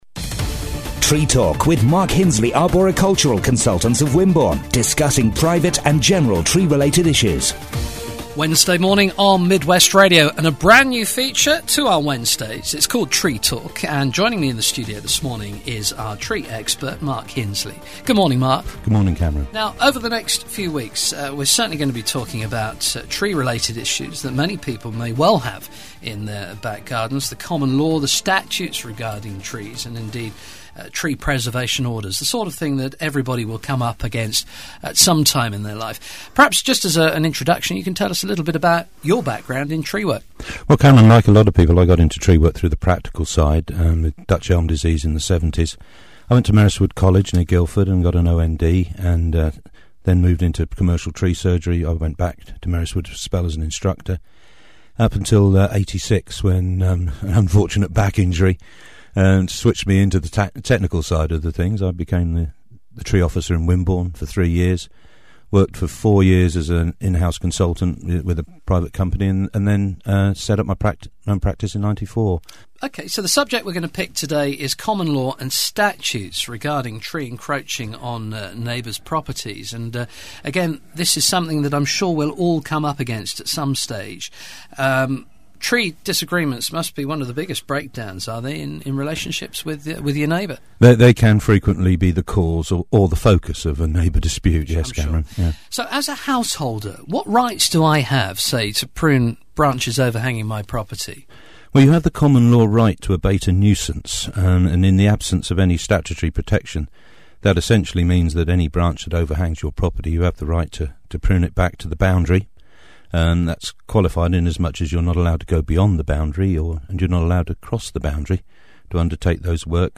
Midwest Radio interview